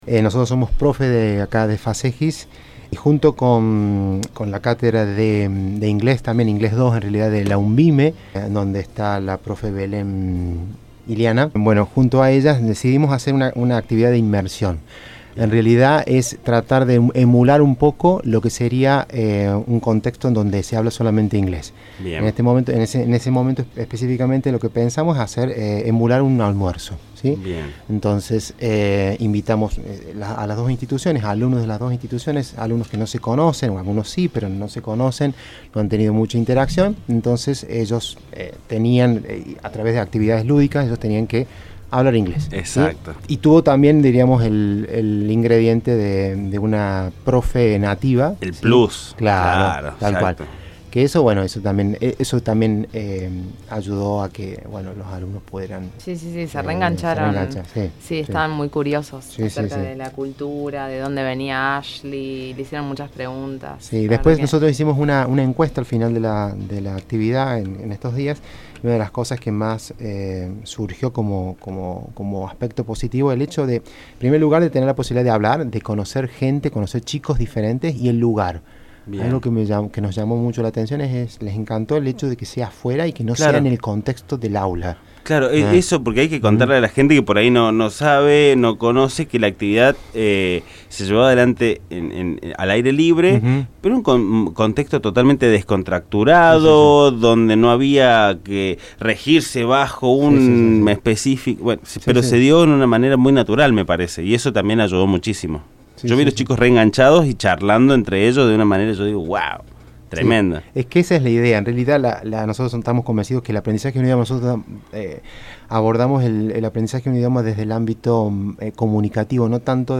Entrevista completa